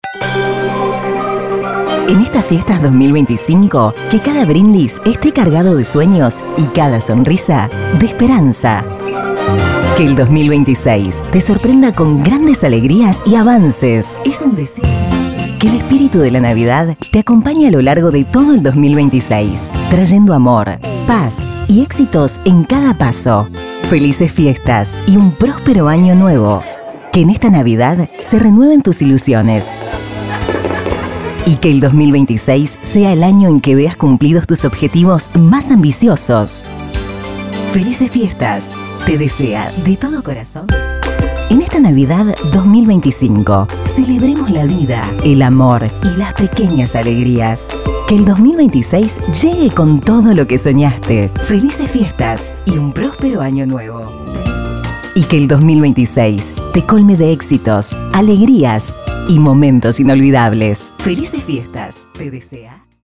Salutaciones premium para venderle a tus anunciantes o personalizarlas con los datos de tu radio.
✅ Fondos musicales y FX de máxima calidad.